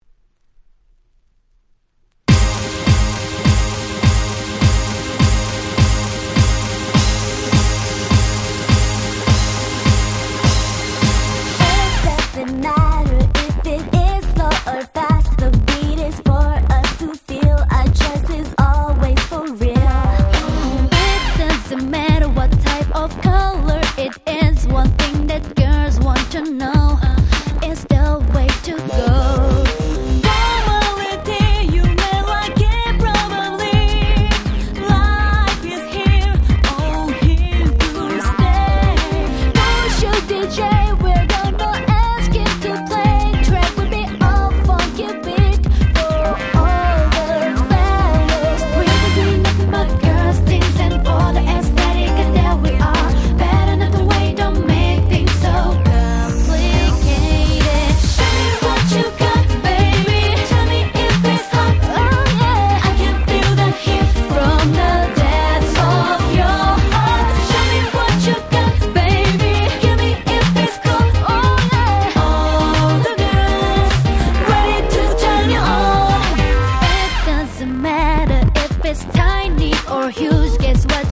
03' Very Nice R&B !!